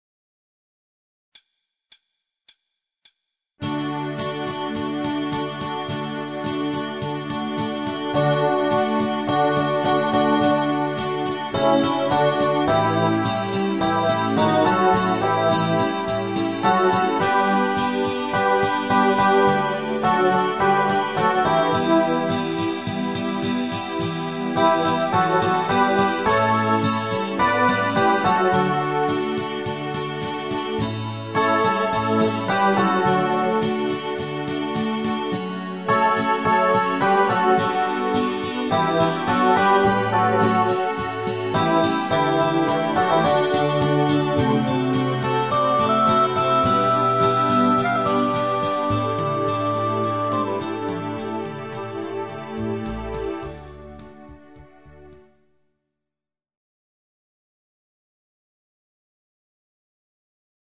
Genre(s): Schlager  Evergreens  |  Rhythmus-Style: Dancebeat